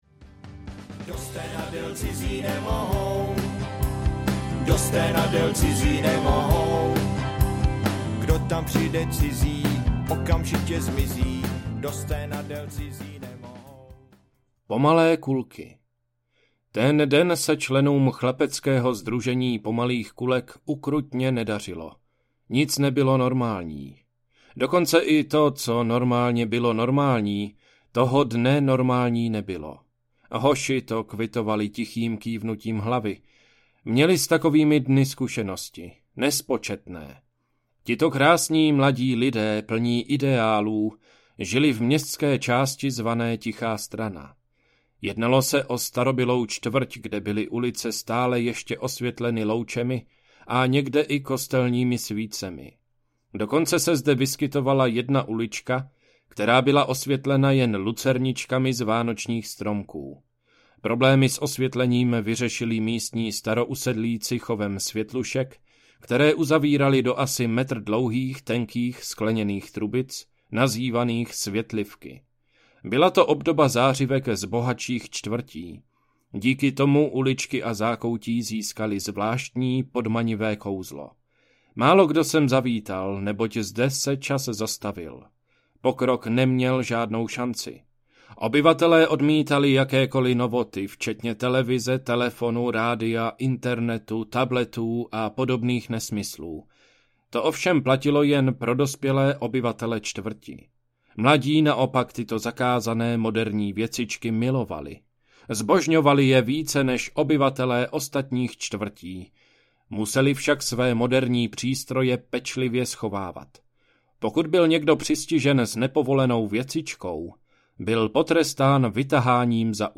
Parta z Tiché strany audiokniha
Ukázka z knihy